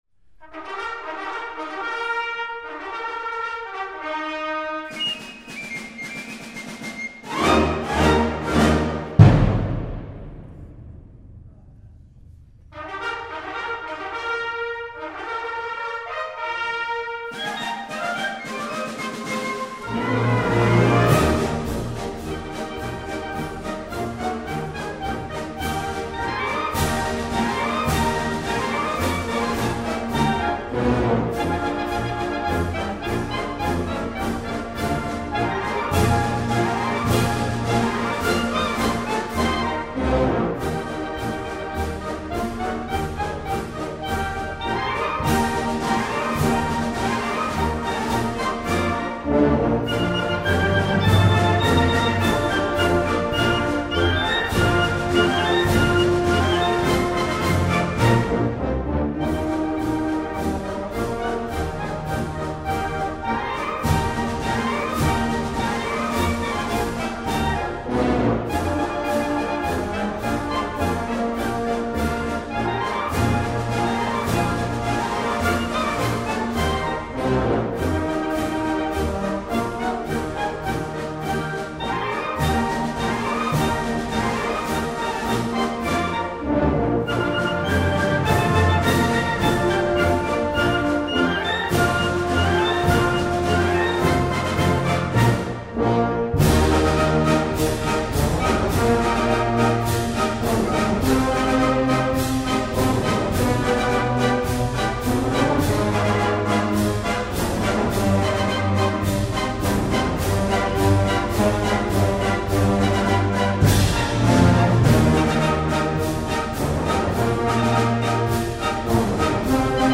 February 25 Concert Band Recordings